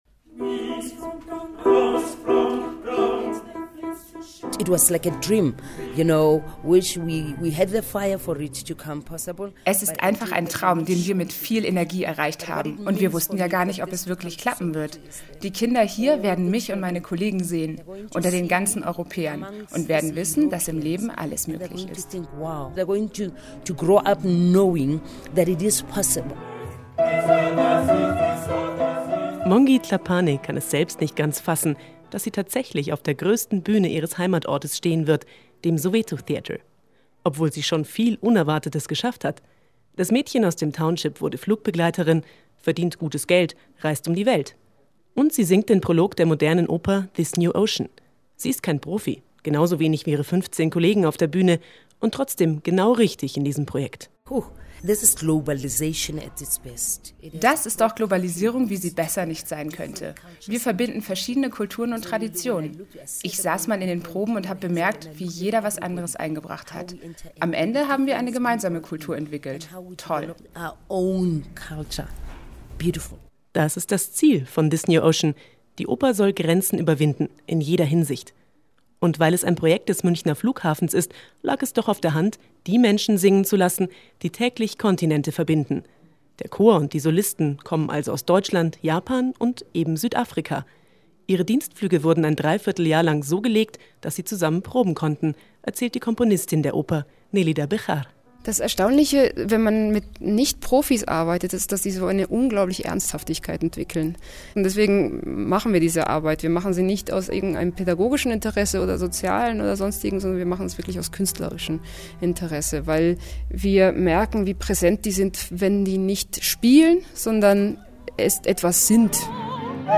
radio report about This New Ocean